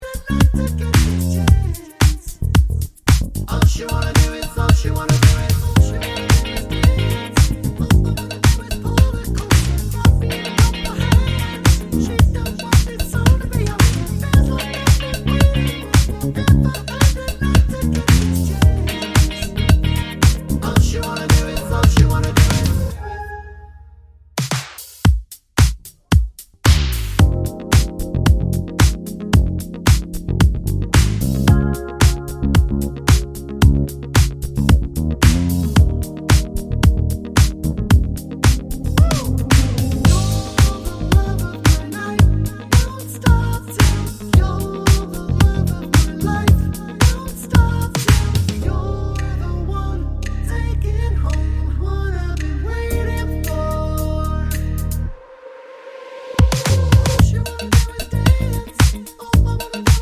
No Octave Harmony Backing Vocals Pop (2020s) 3:16 Buy £1.50